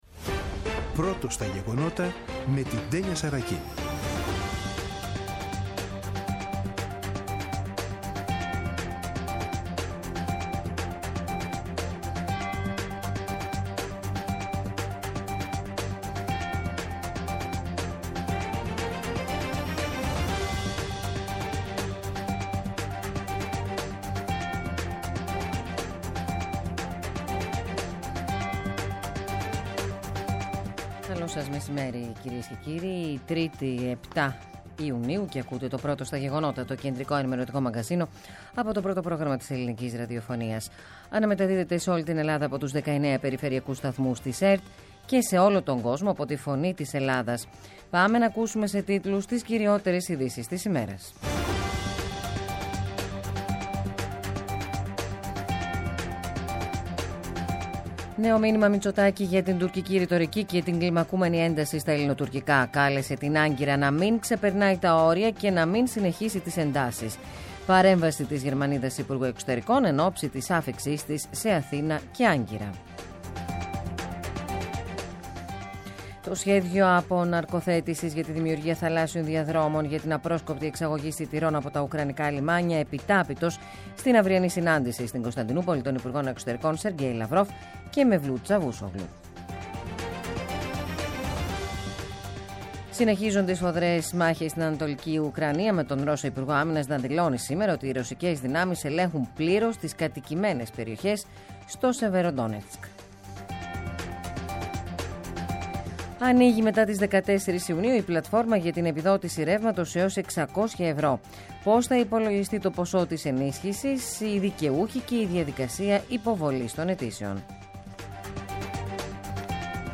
“Πρώτο στα γεγονότα”. Το κεντρικό ενημερωτικό μαγκαζίνο του Α΄ Προγράμματος , από Δευτέρα έως Παρασκευή στις 14.00. Με το μεγαλύτερο δίκτυο ανταποκριτών σε όλη τη χώρα, αναλυτικά ρεπορτάζ και συνεντεύξεις επικαιρότητας.